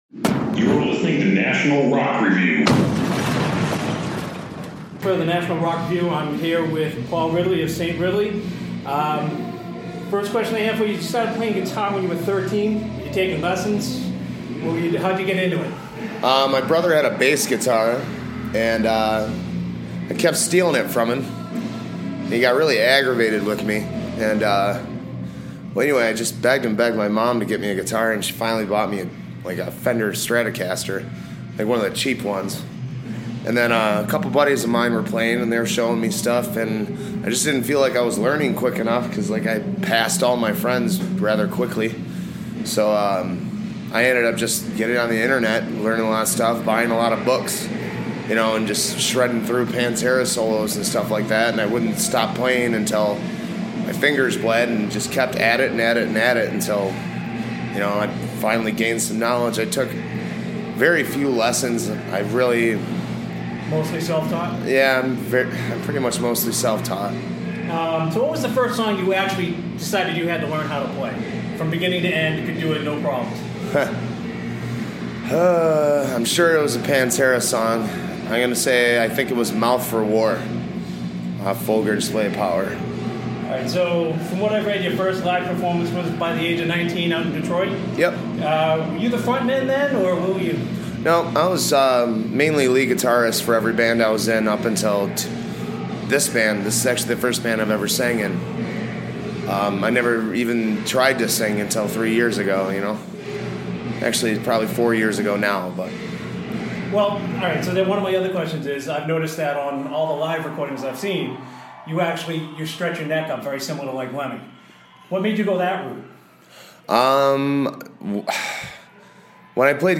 A Saint Confesses His Sins – An Interview